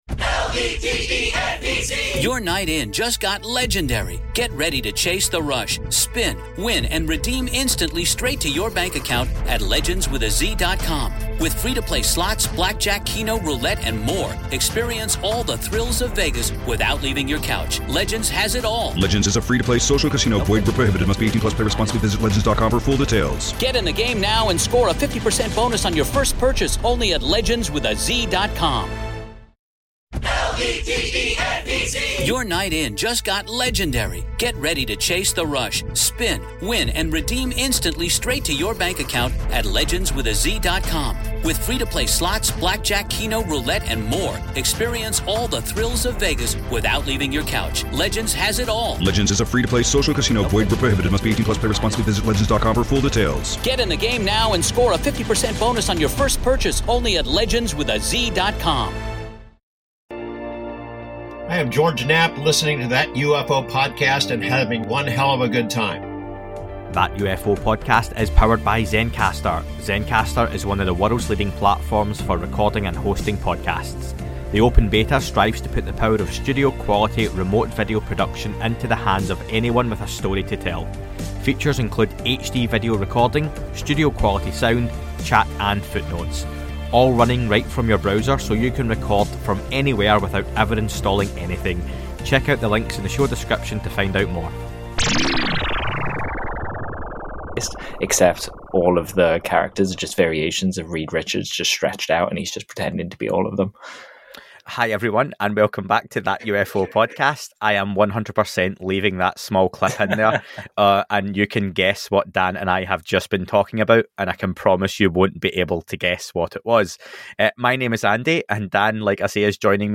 Ariel Phenomenon Documentary, Review